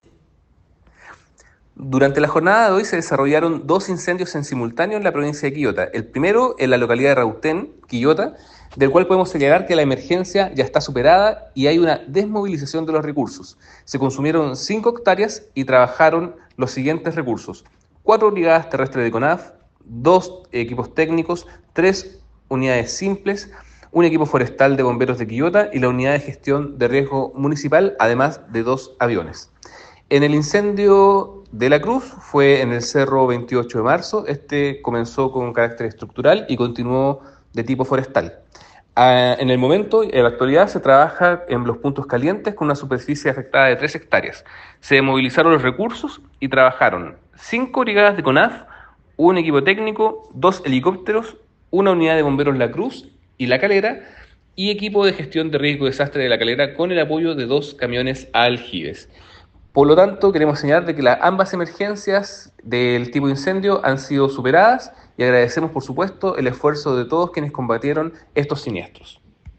“Trabajaron cuatro unidades terrestres de Conaf, dos equipos técnicos, tres unidades simples, un equipo forestal de Bomberos de Quillota, la Unidad de Gestión de Riesgo municipal y además de dos aviones”, detalló el delegado presidencial provincial de Quillota, José Raúl Orrego.
delegado-presidencial-provincial-de-Quillota-Jose-Raul-Orrego.m4a